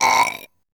belch.wav